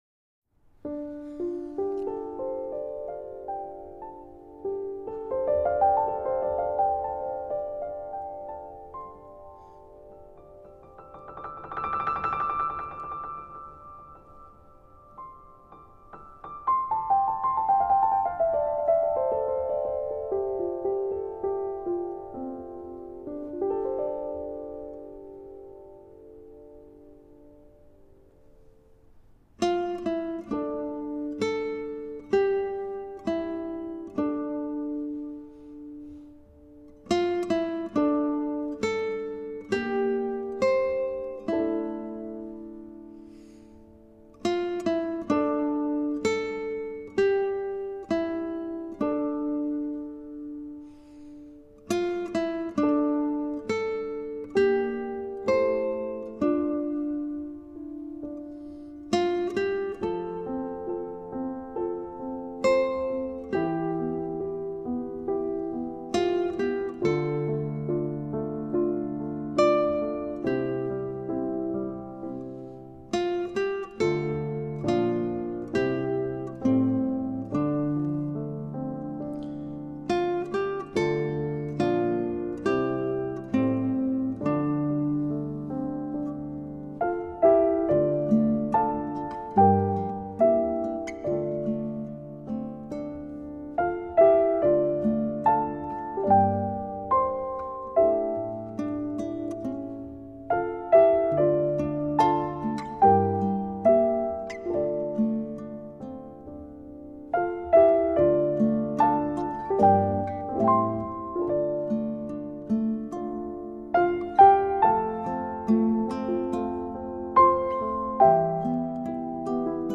新世纪——身·心·灵——深度保养
本系列所有音乐，都在日本「八之岳山麓」製作完成。
本辑「月」引导你进入幸福感的宁静世界。